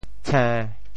潮语发音
cên1